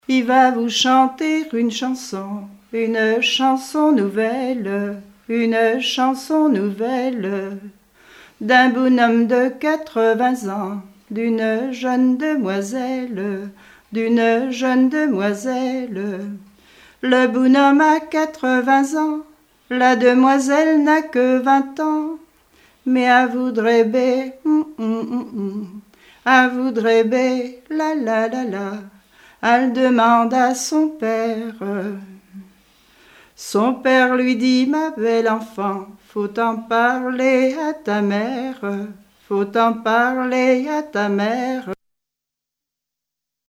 Genre strophique
Répertoire de chansons populaires et traditionnelles